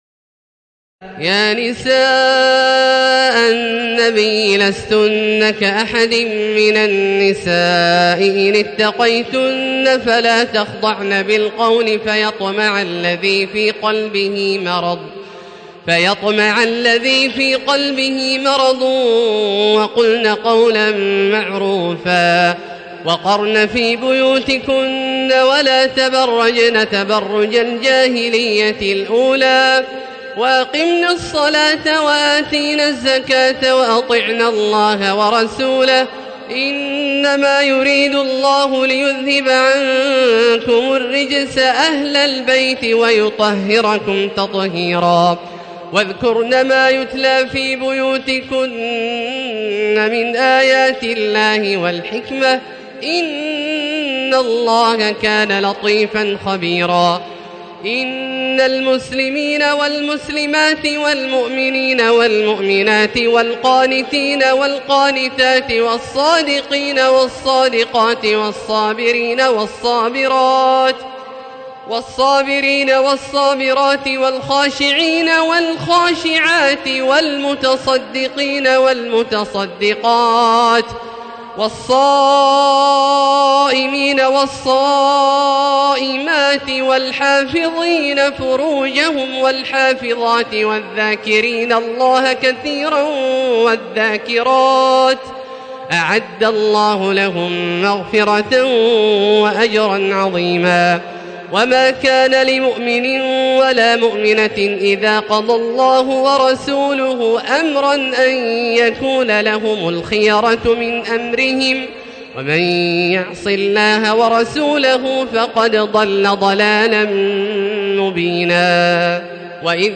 تراويح ليلة 21 رمضان 1437هـ من سور الأحزاب (32-73) وسبأ (1-23) Taraweeh 21 st night Ramadan 1437H from Surah Al-Ahzaab and Saba > تراويح الحرم المكي عام 1437 🕋 > التراويح - تلاوات الحرمين